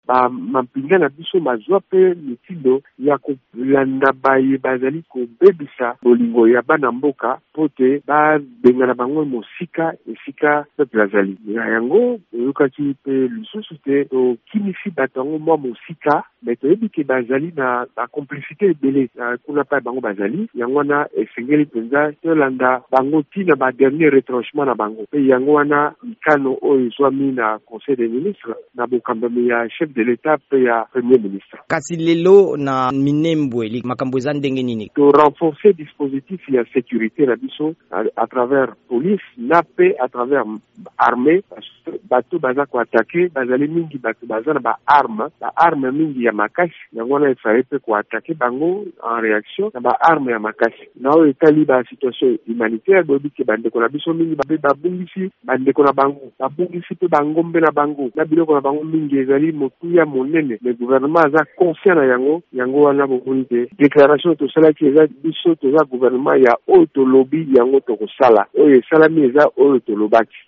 Eyano ya mbulamatari ya ekolo Congo démocratique nsima na botamboli ya ba Banyamulenge awa na Washington mpo na kopamela bobomami na bango na Minembwe, na Hauts plateaux ya Sud-Kivu. VOA Lingala ebengaki na nzela ya singa ministre molobeli ya mbulamatari ya Premier ministre Sylvestre Ilunga Ilunkamba, Jolino Makelele.